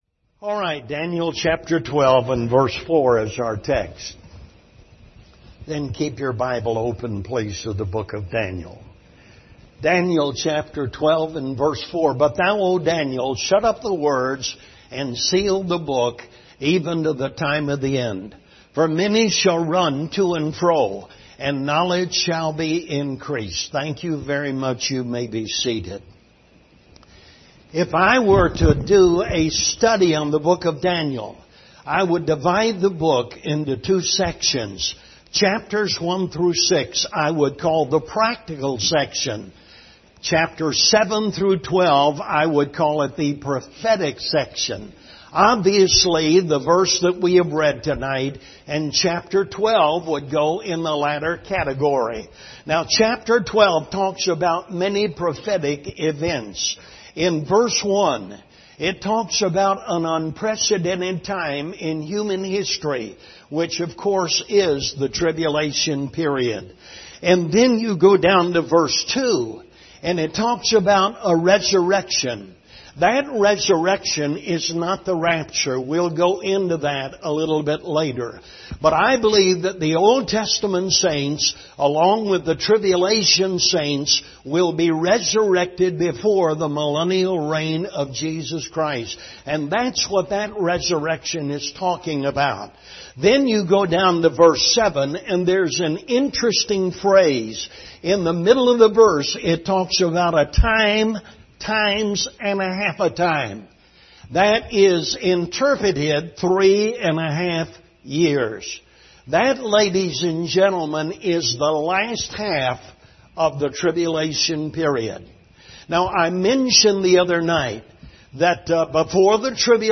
Service Type: Revival Service